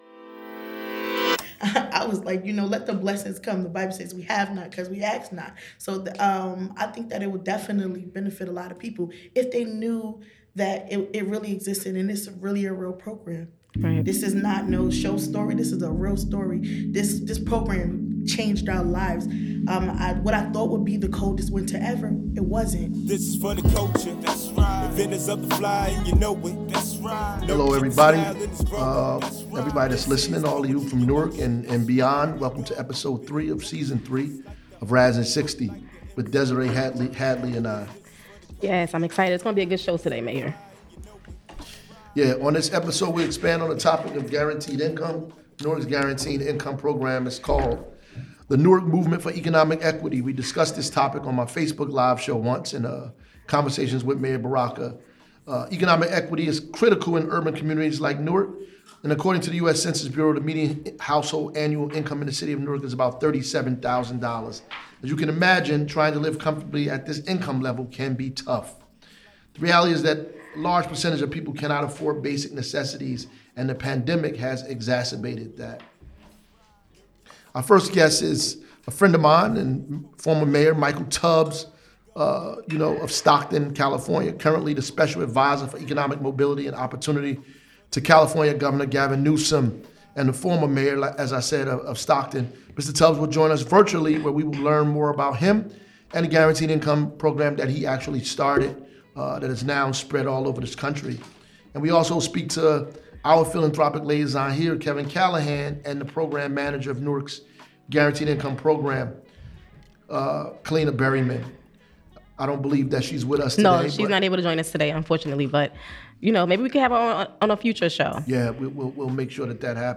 Mr. Tubbs joins virtually to explain the guaranteed income program he started in Stockton, CA.&nbsp